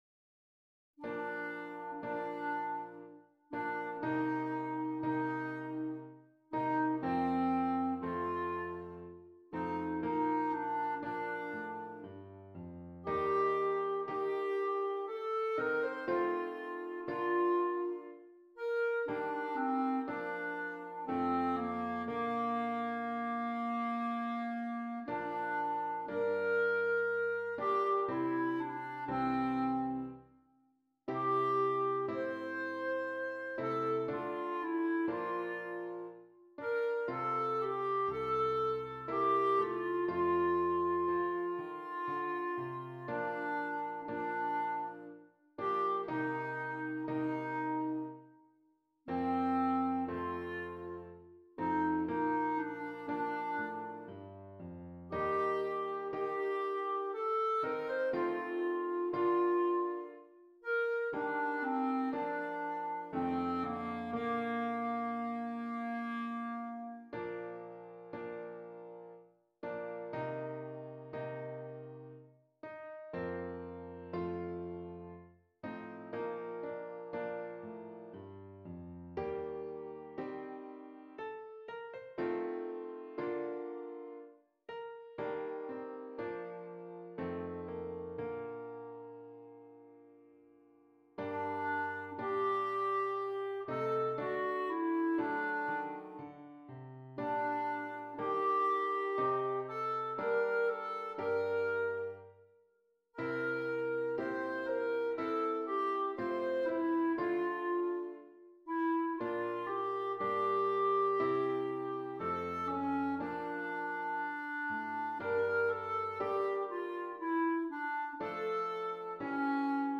Clarinet and Keyboard